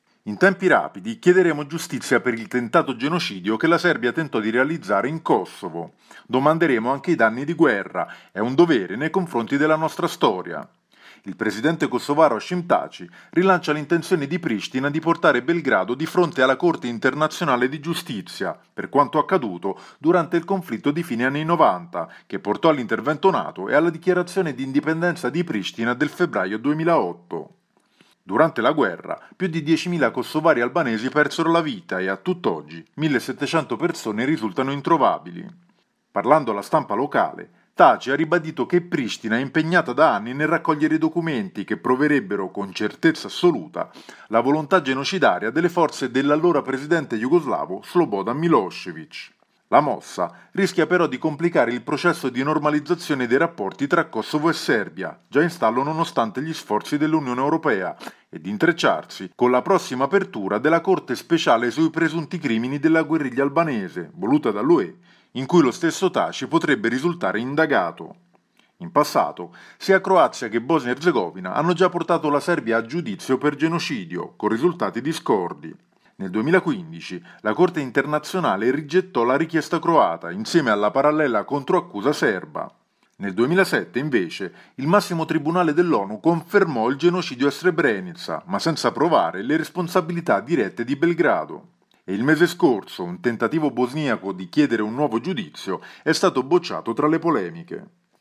per il GR di Radio Capodistria